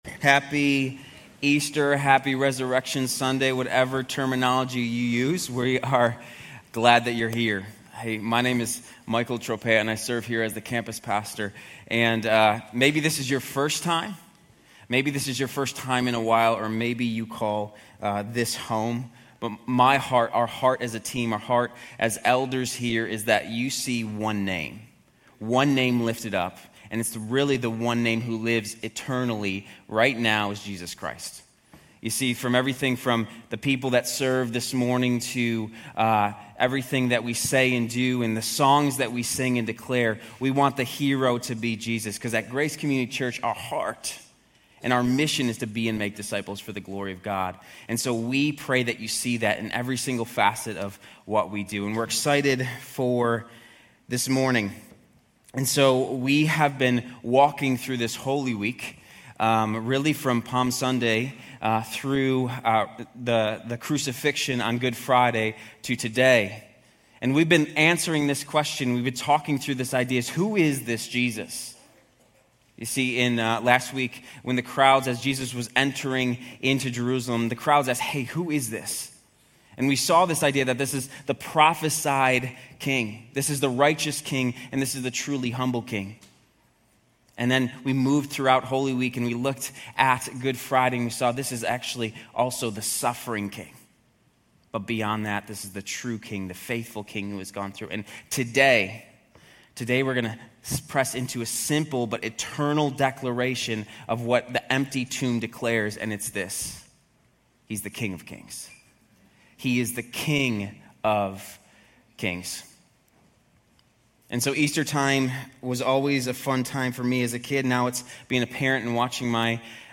Grace Community Church University Blvd Campus Sermons 4_20 University Blvd Campus Apr 20 2025 | 00:29:26 1x 00:00 / 00:29:26 Subscribe Share RSS Feed Share Link Embed